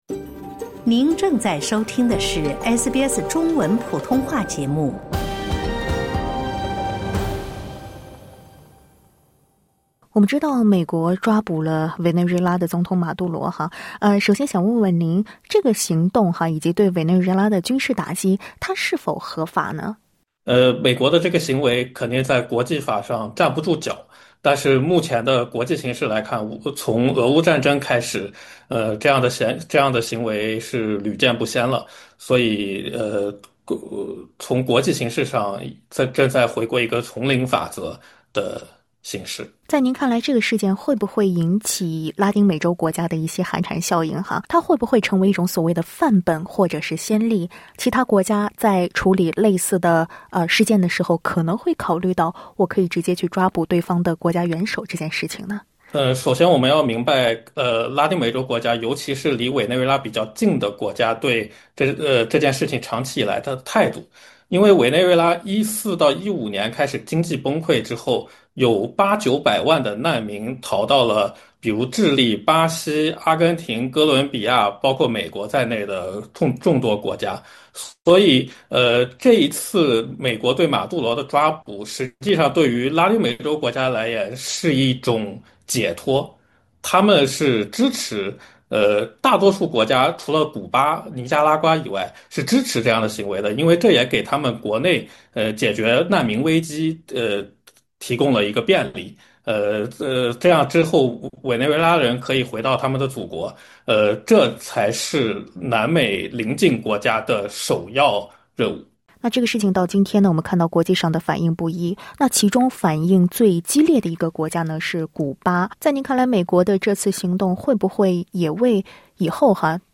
SBS Chinese 09:07 cmn 采访仅为嘉宾观点，不代表SBS立场。